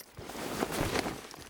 looting_12.ogg